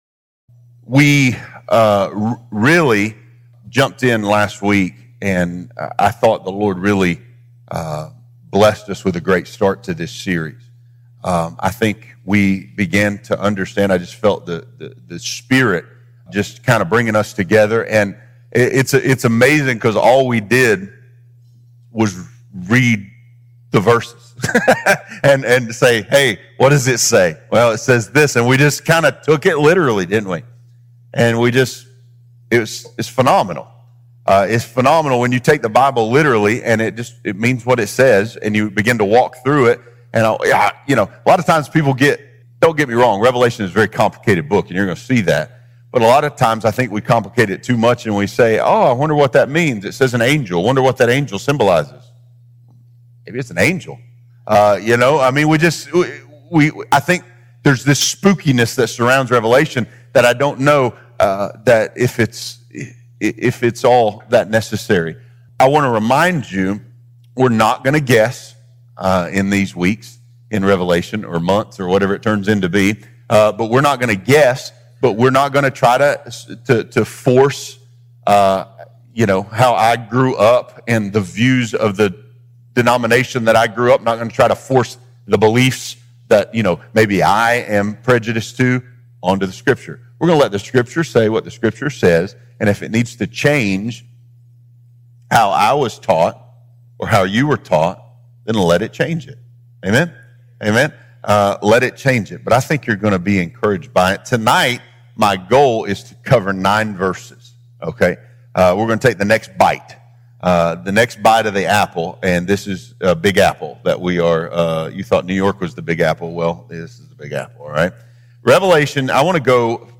Have you ever wondered what the book of Revelation really means and how it relates to the current times? This is a verse-by-verse, bite-by-bite, in-depth Bible study that does not speculate or guess; letting Scripture speak and authenticate this important, but often overlooked book in the Bible.